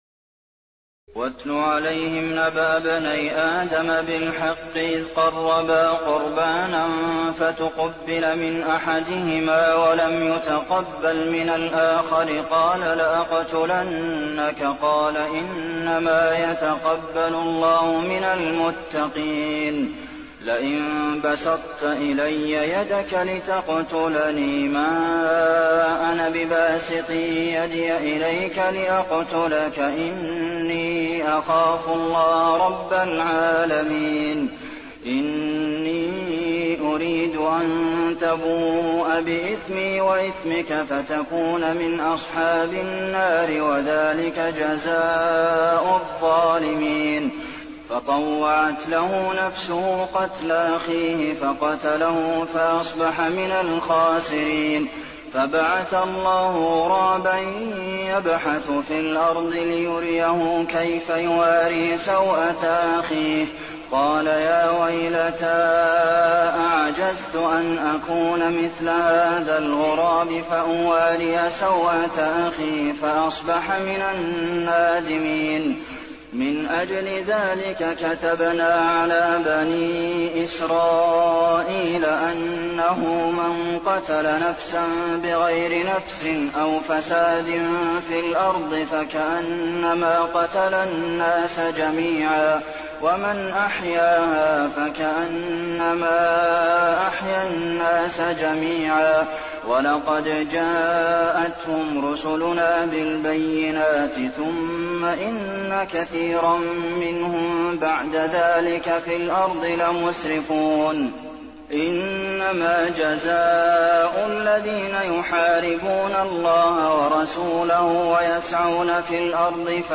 تراويح الليلة السادسة رمضان 1422هـ من سورة المائدة (27-81) Taraweeh 6 st night Ramadan 1422H from Surah AlMa'idah > تراويح الحرم النبوي عام 1422 🕌 > التراويح - تلاوات الحرمين